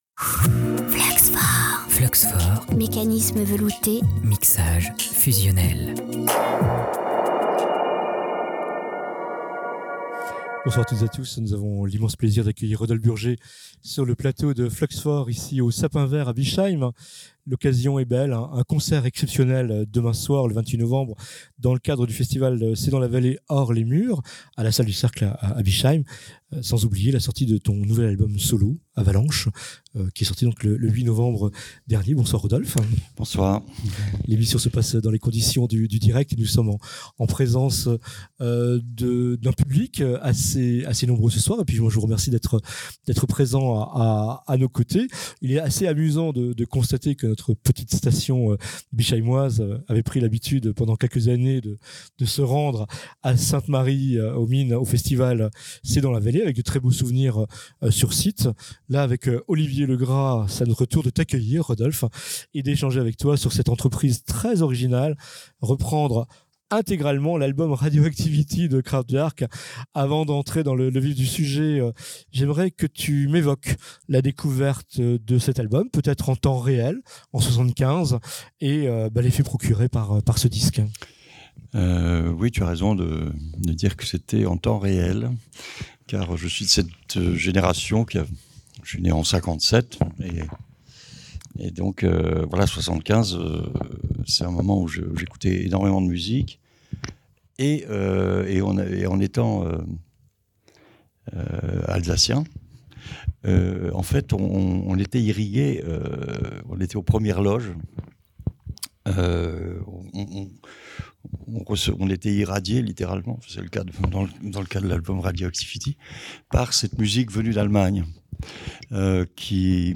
Bischheim, novembre 2024 – Hier soir, sur notre plateau, l'ami Rodolphe Burger nous a ouvert les portes d’un univers fascinant : celui de Kraftwerk, groupe mythique et pionnier de la musique électronique.